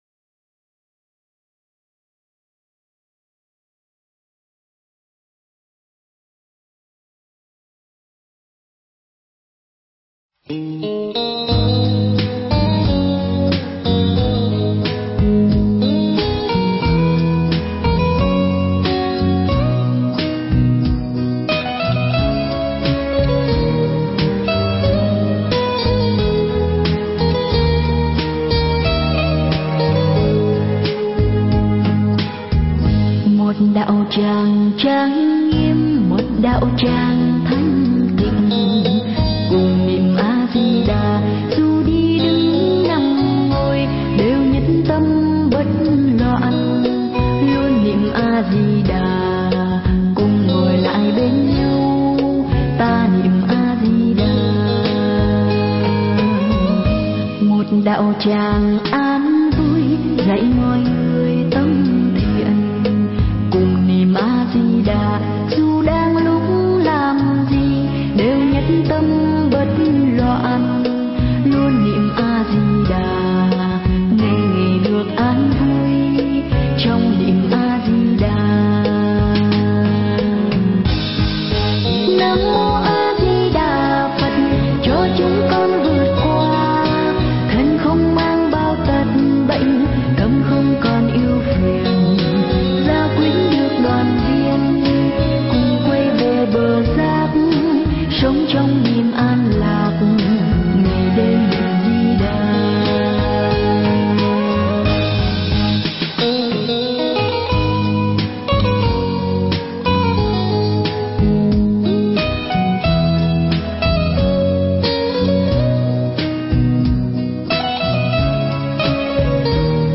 Nghe Mp3 thuyết pháp Một Cõi Đi Về Phần 2
Nghe mp3 pháp thoại Một Cõi Đi Về Phần 2